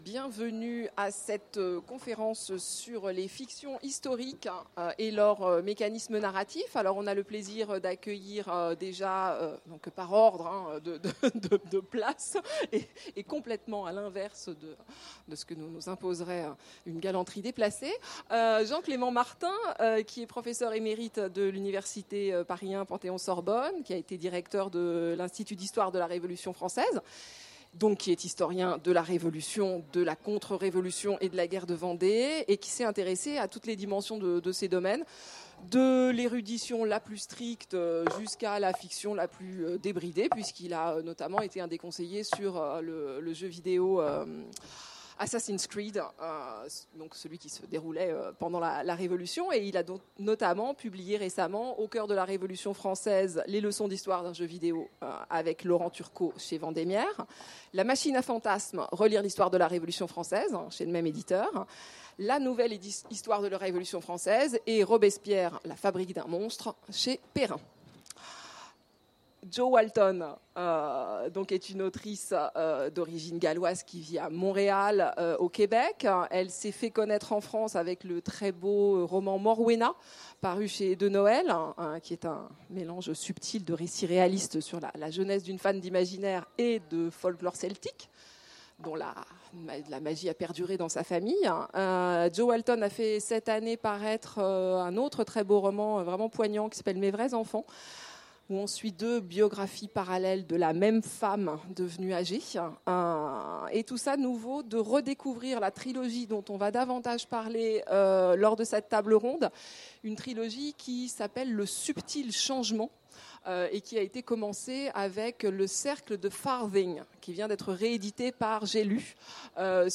Mots-clés Histoire Conférence Partager cet article